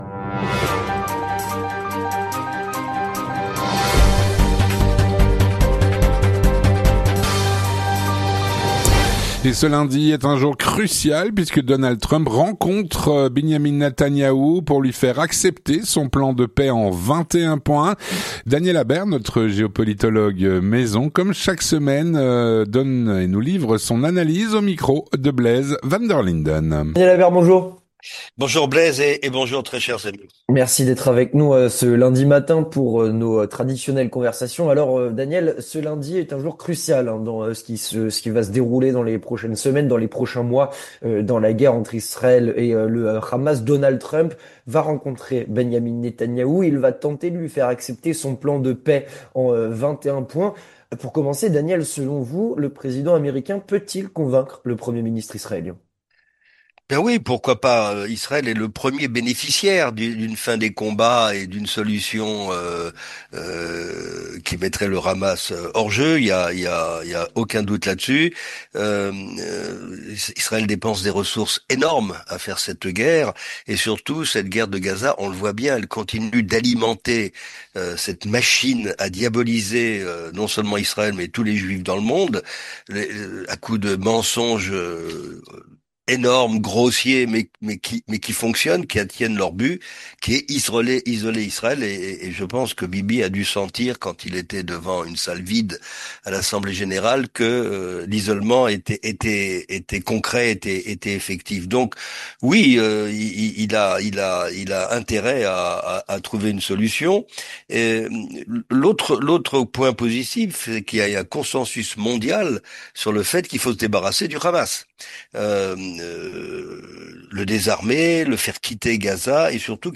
Il répond aux questions